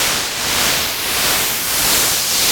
RI_RhythNoise_95-02.wav